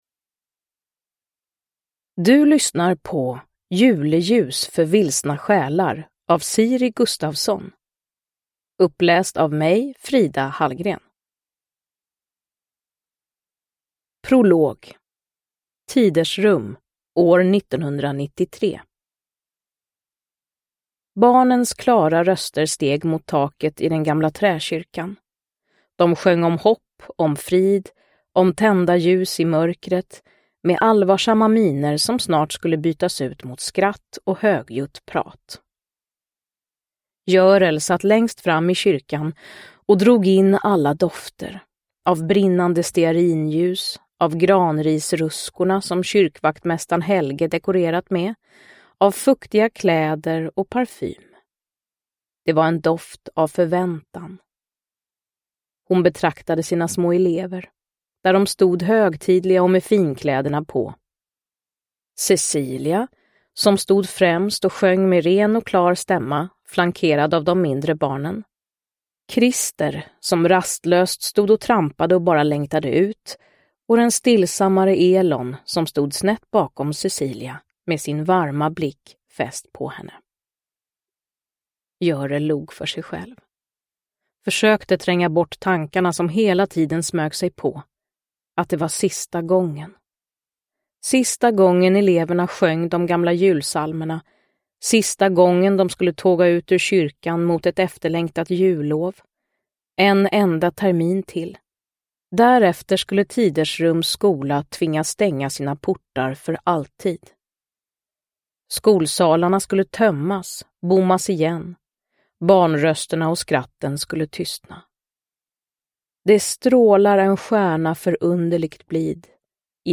Juleljus för vilsna själar – Ljudbok – Laddas ner
Uppläsare: Frida Hallgren